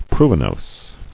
[pru·i·nose]